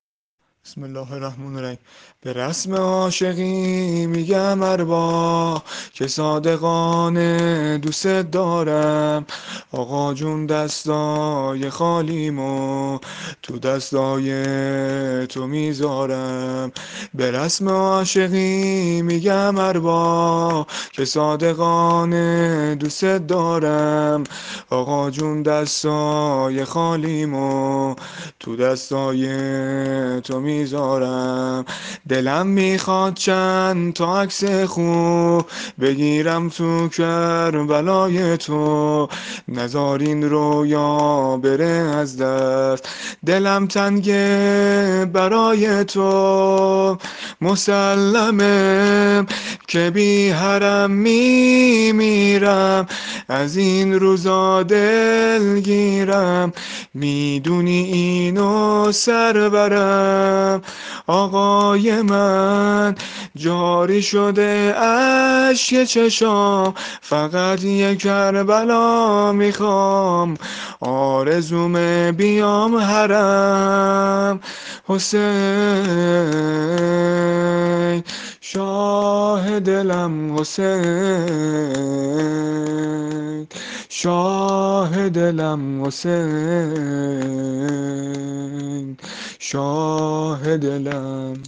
شور مناجات با امام حسین علیه السلام -(به رسم عاشقی میگم ارباب)